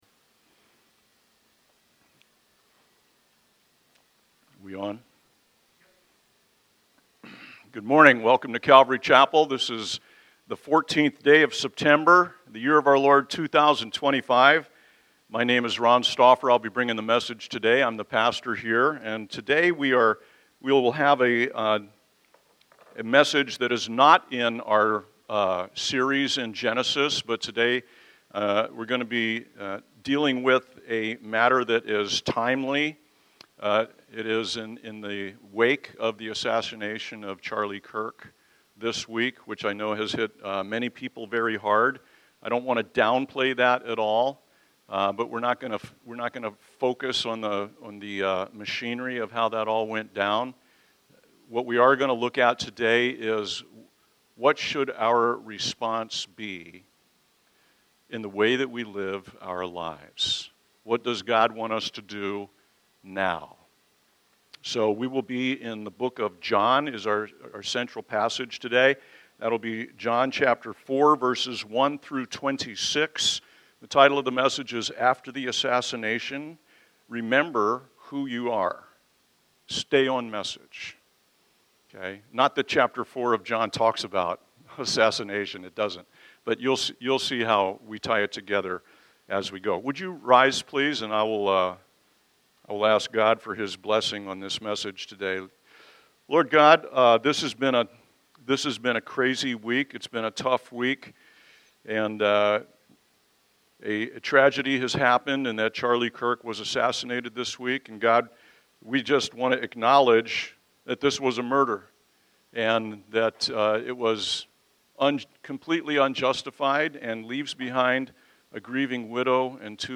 by Calvary Chapel Leesburg | Sep 14, 2025 | Sermons